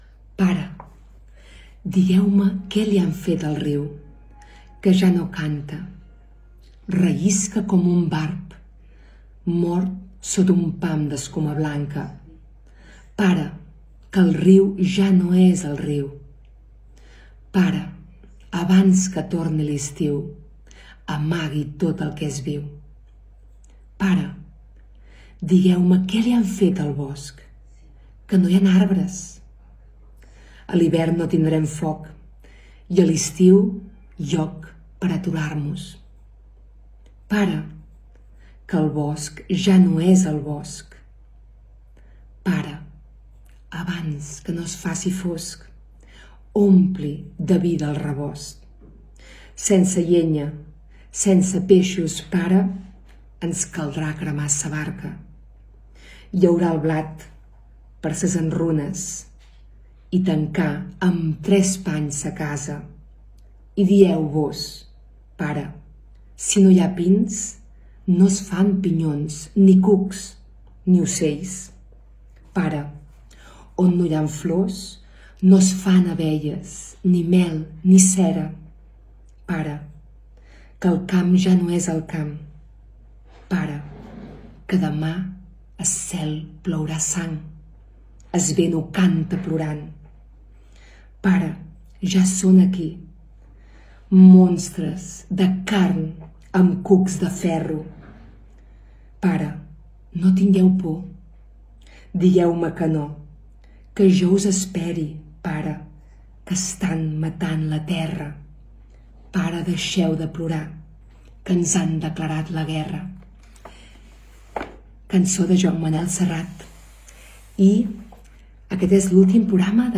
Lectura de la lletra de la canço "Pare" de Joan Manuel Serrat. Comiat de l'últim programa de la temporada